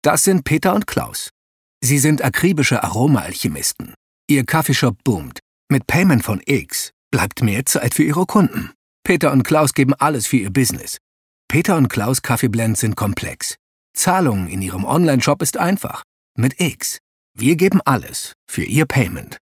hell, fein, zart, markant, sehr variabel
Commercial (Werbung)